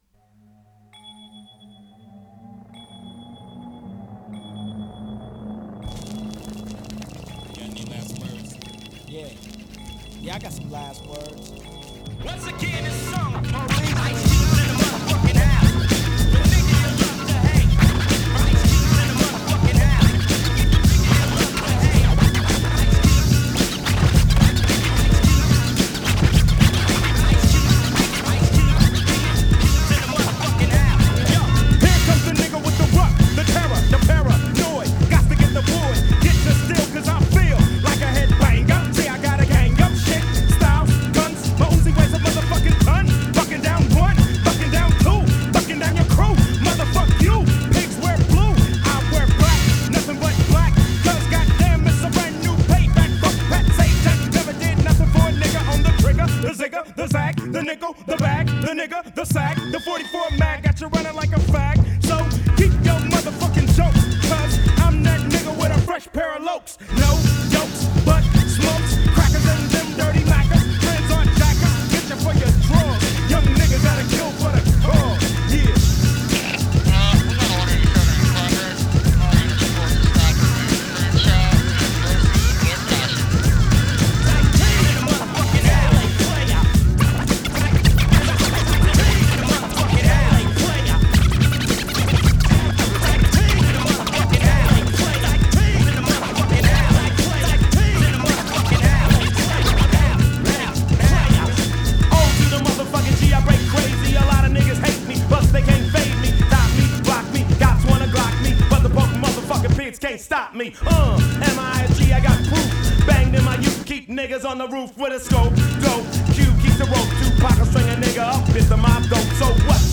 hip hop rap